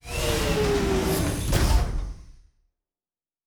Door 4 Close.wav